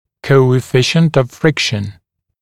[ˌkəuɪ’fɪʃnt əv ‘frɪkʃn][ˌкоуи’фишнт ов ‘фрикшн]коэффициент трения